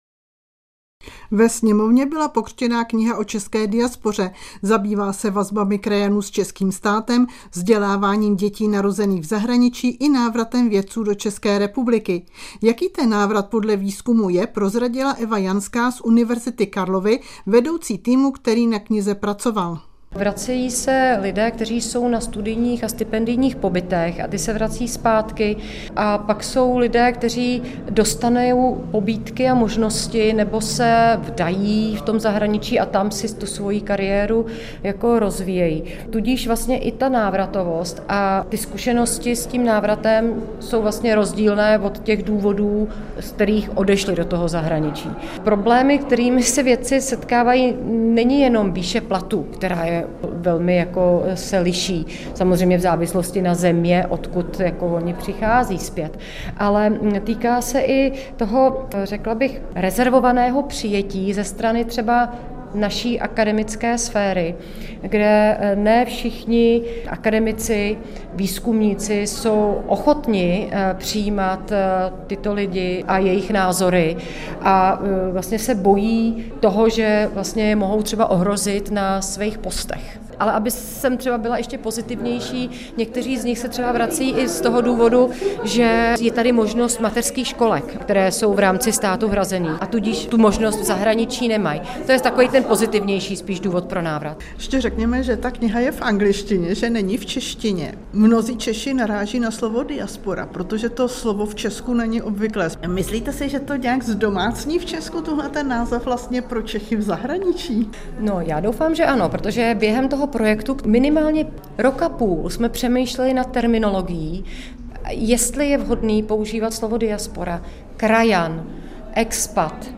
Rozhovor s doc.